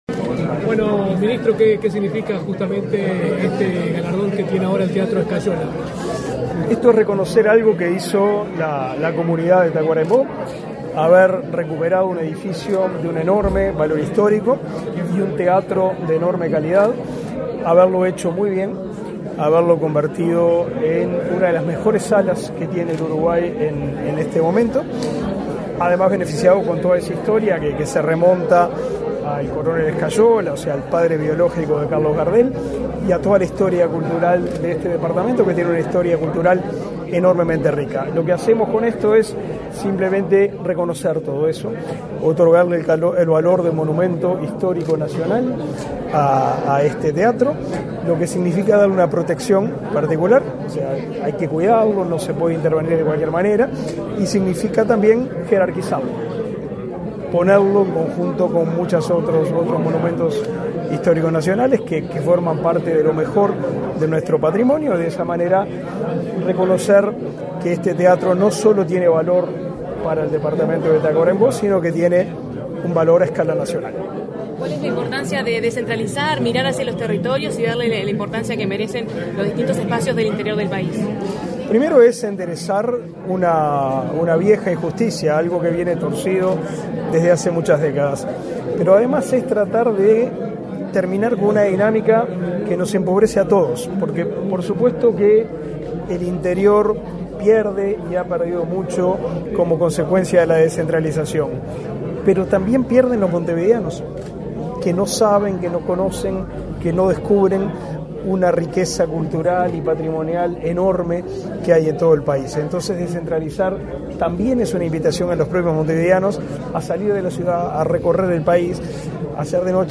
Declaraciones a la prensa del ministro de Educación y Cultura, Pablo da Silveira
Declaraciones a la prensa del ministro de Educación y Cultura, Pablo da Silveira 11/09/2024 Compartir Facebook X Copiar enlace WhatsApp LinkedIn Tras participar en el acto de declaratoria del teatro Escayola de Tacuarembó como Monumento Histórico Nacional, este 11 de setiembre, el ministro de Educación y Cultura, Pablo da Silveira, realizó declaraciones a la prensa.